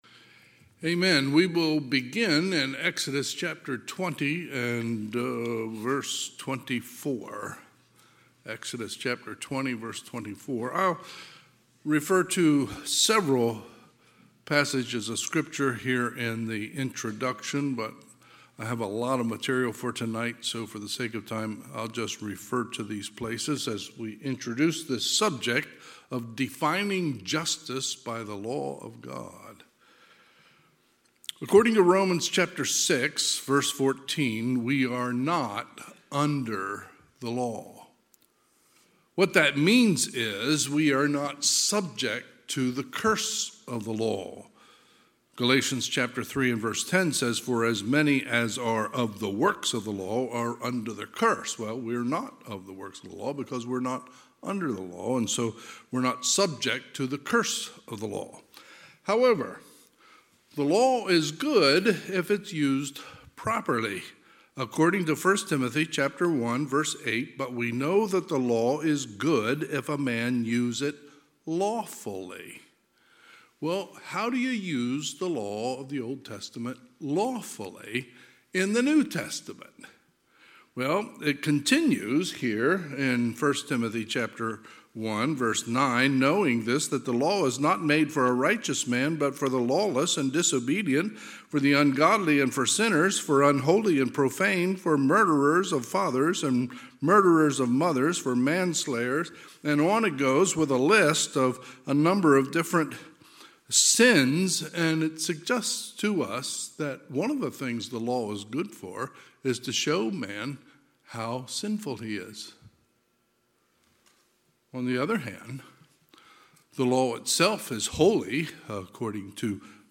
2023 Sermons admin Exodus 21:1 – 22:15